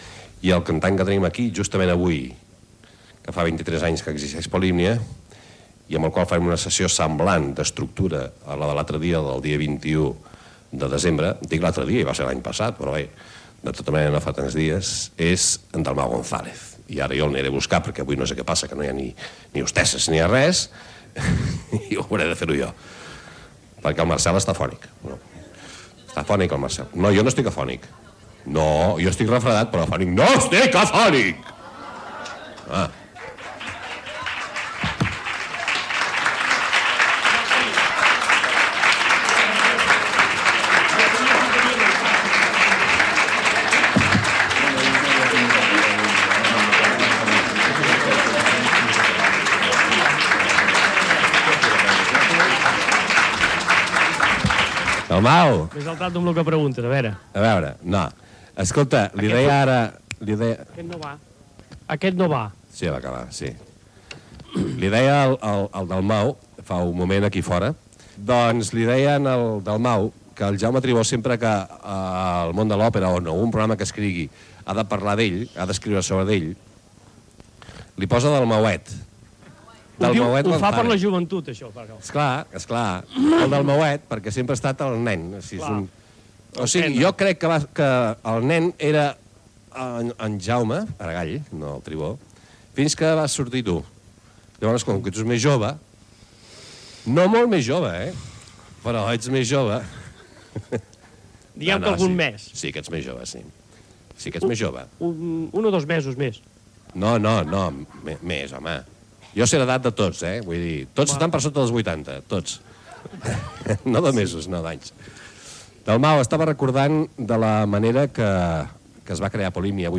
Musical
Espai fet a l'Estudi 1 de RNE a Barcelona, a la sisena planta del Passeig de Gràcia 1, de Barcelona.